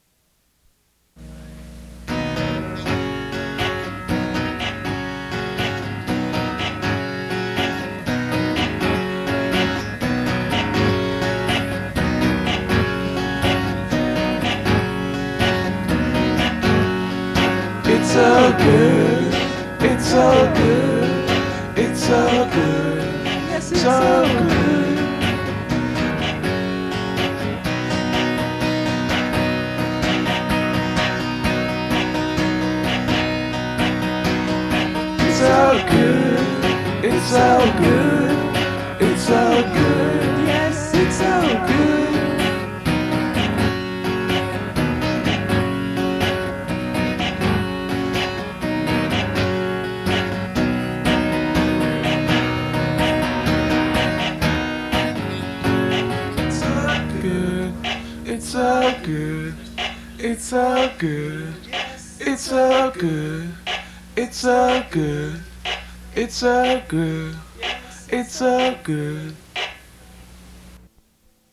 Enjoy the low-fi songs of yearning.